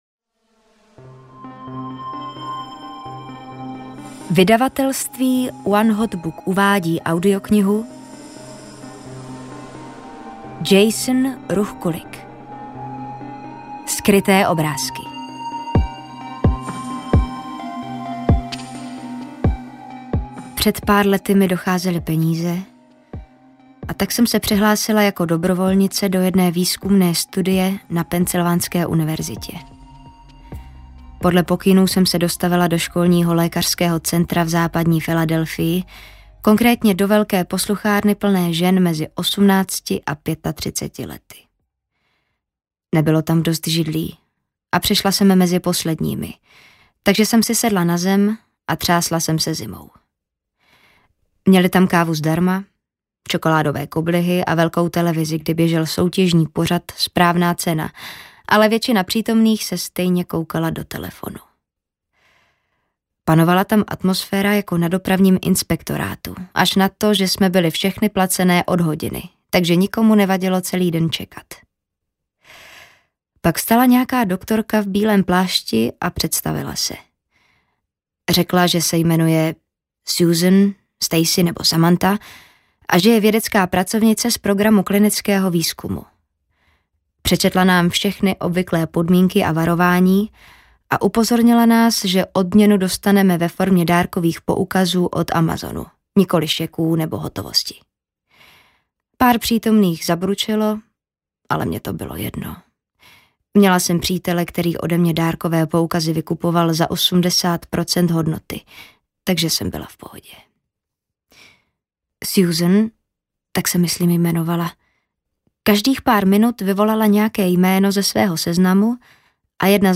Skryté obrázky audiokniha
Ukázka z knihy
skryte-obrazky-audiokniha